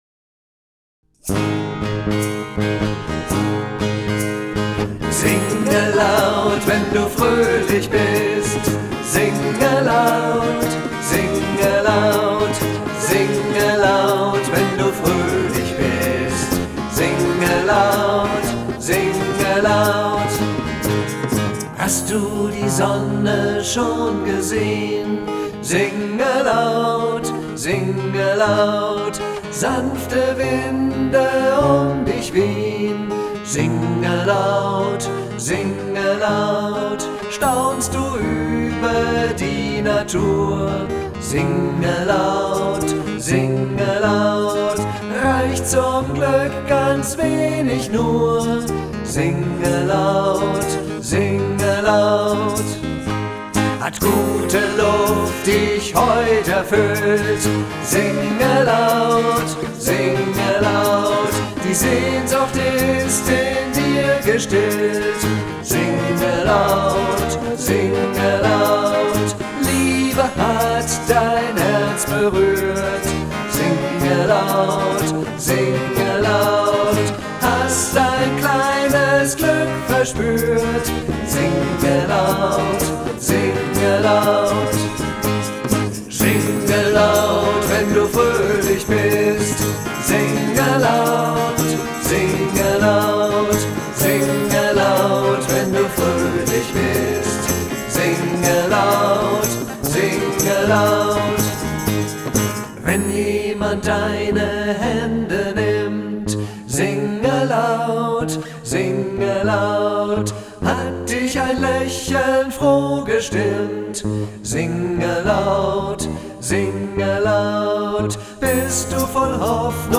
Ein flottes Lied, das zum Mitsingen und Mitklatschen einlädt